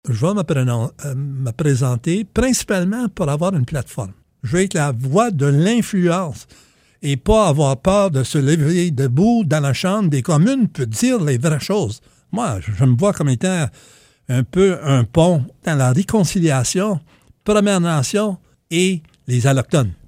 Monsieur Whiteduck a expliqué lors de son passage à l’émission L’heure juste mercredi, vouloir être le pont de la réconciliation entre les Premières Nations et les allochtones. Il a dit vouloir utiliser sa candidature comme une plateforme.